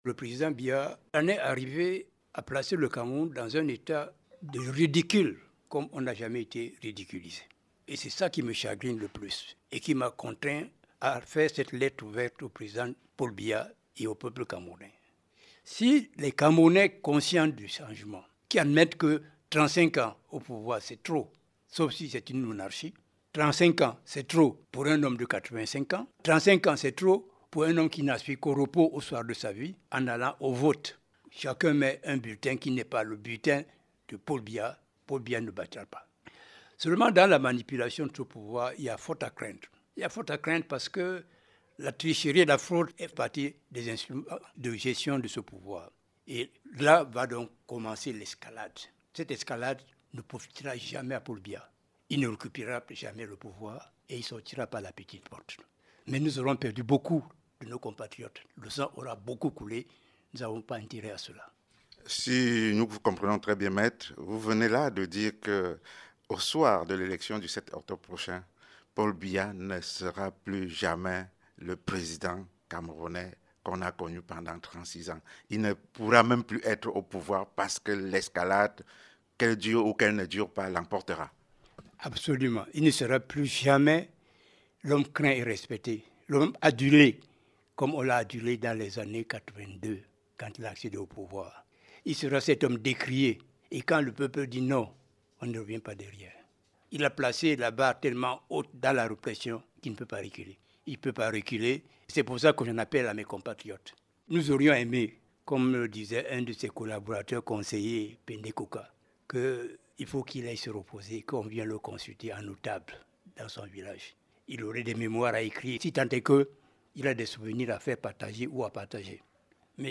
Il est interrogé par notre correspondant à Douala